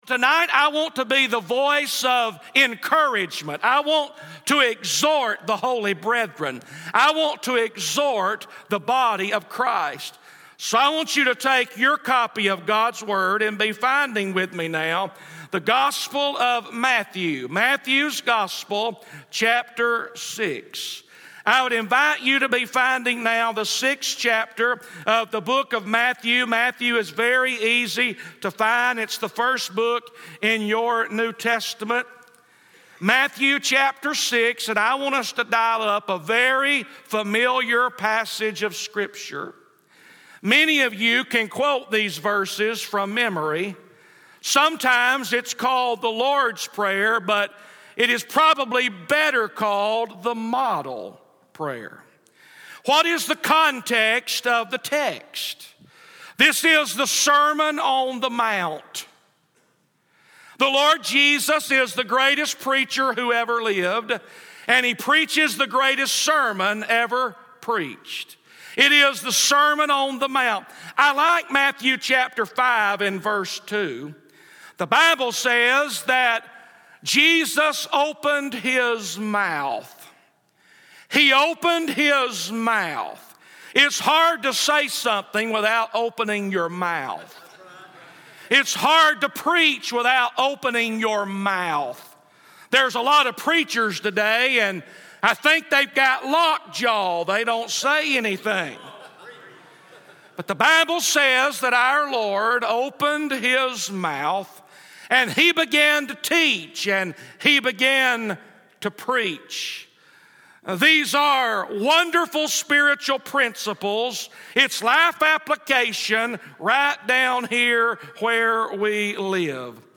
From the evening session of the Real Momentum Conference on Friday, August 2, 2019